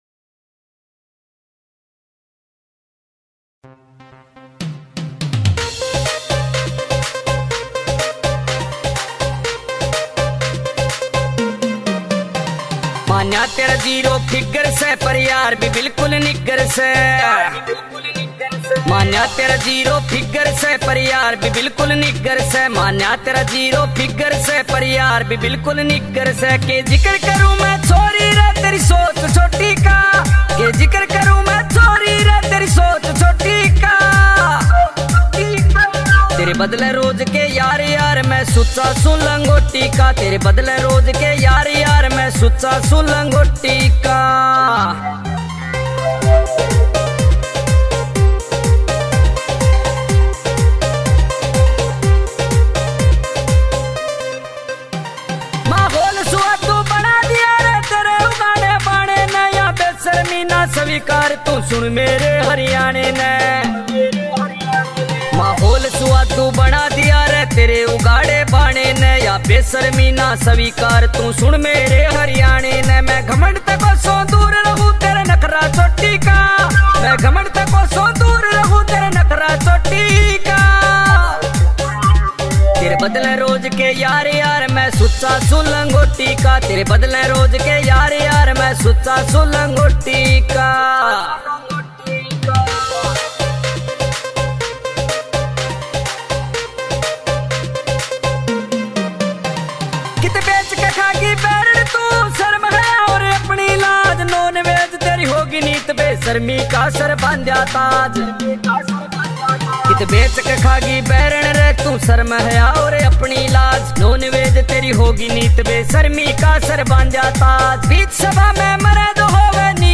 [ Haryanvi Songs ]